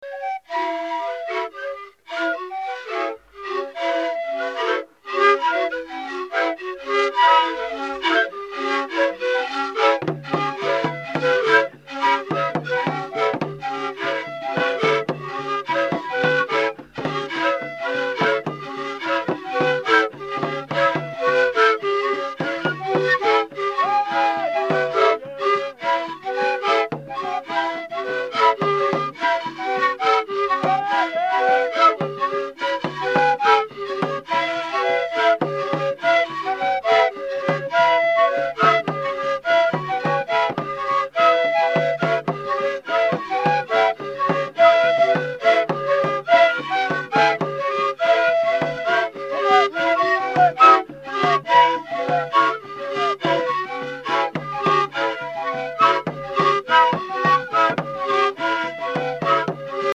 Whistle
The majority of wind instruments from Africa in the KMMA organological collection are small signalling flutes or whistles.
A mouthpiece (blowhole) is cut on the upper side of the body, and on one or both sides, a sound hole is bored, making it possible to produce two or three tones.